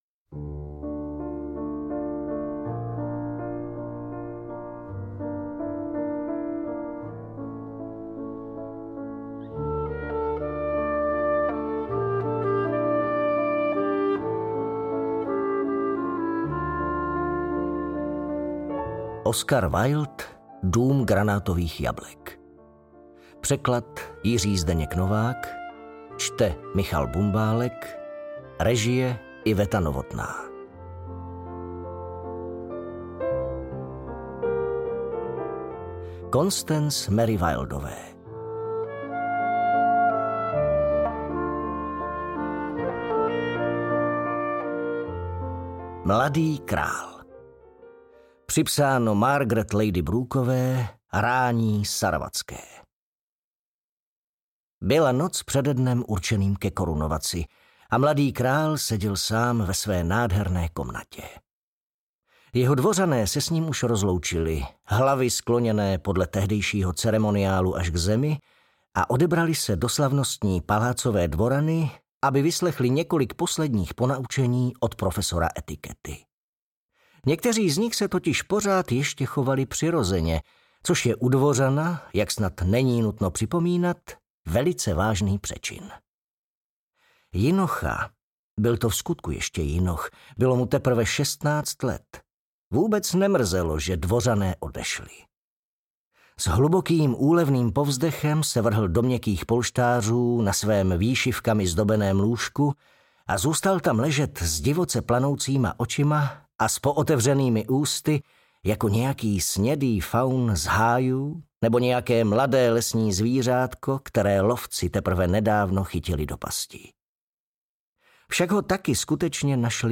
Pohádky ze souboru Dům granátových jablek od Oscara Wilda poprvé v audioknižní podobě! Oscar Wilde během svého života napsal dva soubory pohádek.